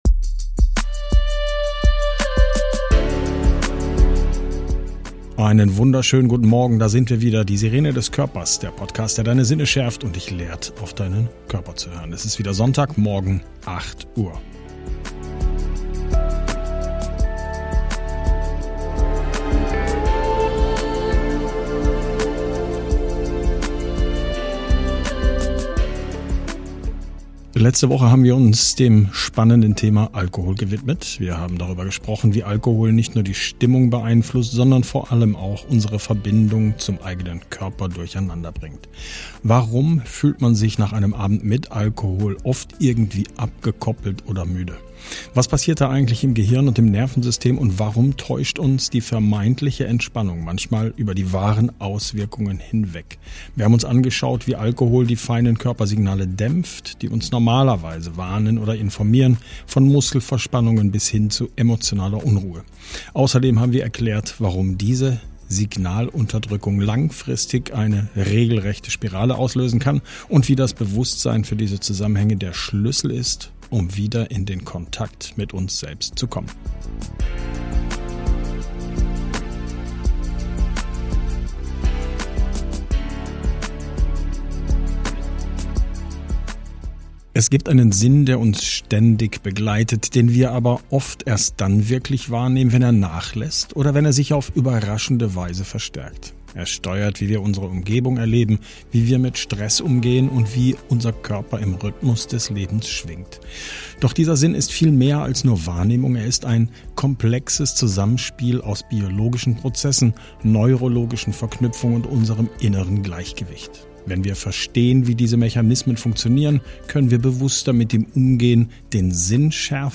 Ein Gespräch, das nicht nur die Augen schärft, sondern auch die Perspektive auf das große Ganze verändert.